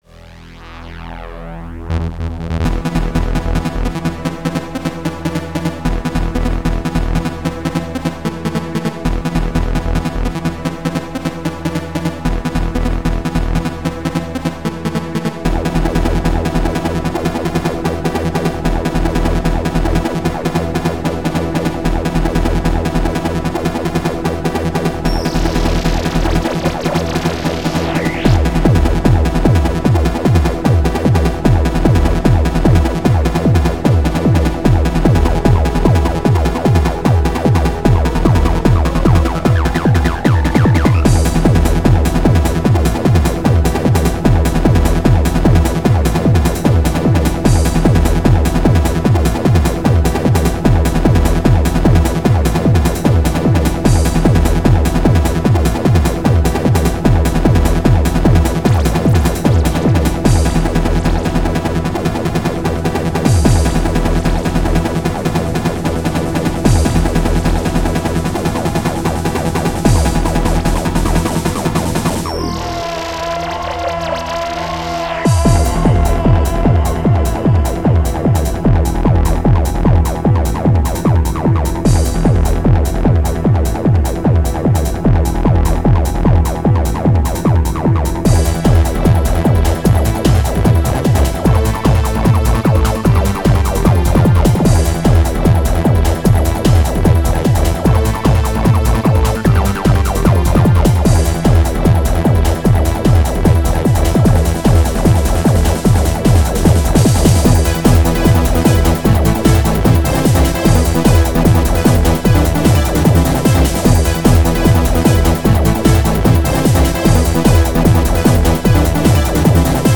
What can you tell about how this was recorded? xm (FastTracker 2 v1.04)